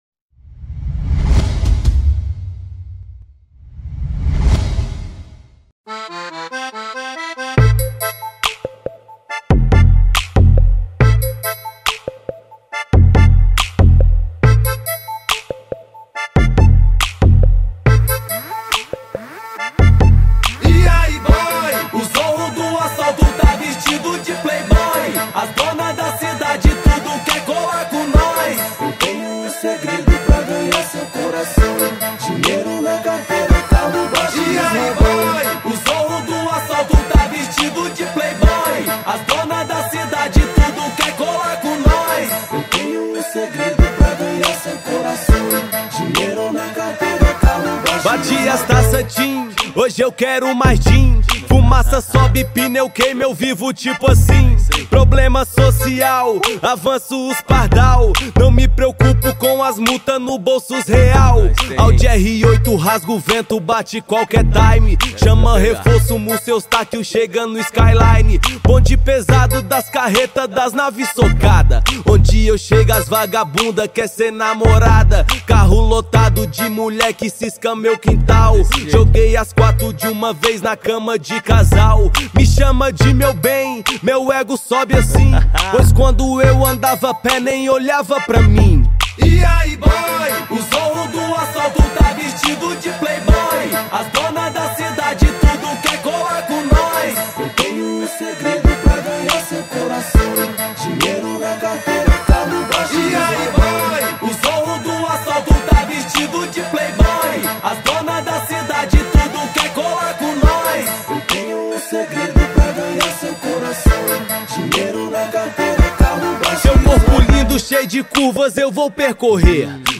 2024-05-10 21:20:56 Gênero: Hip Hop Views